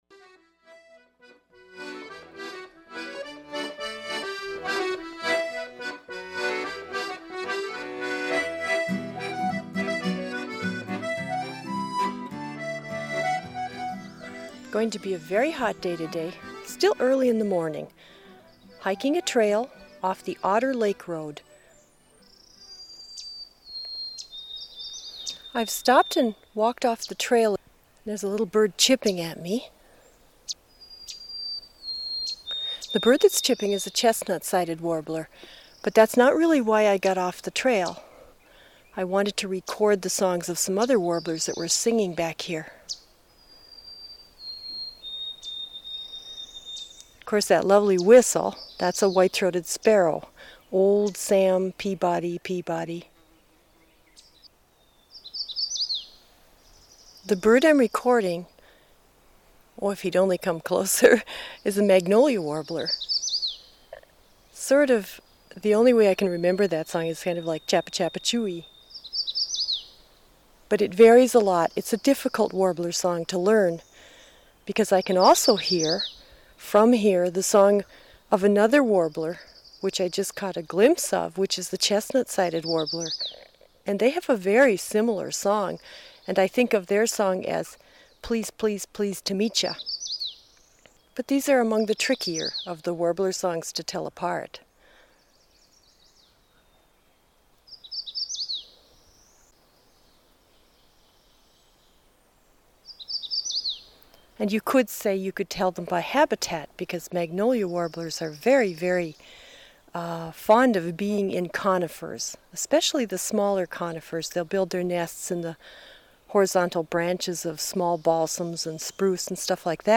Recorded in the fields and woodlands of Northeastern Minnesota